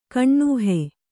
♪ kaṇṇūhe